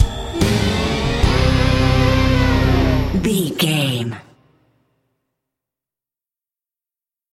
In-crescendo
Thriller
Aeolian/Minor
synthesiser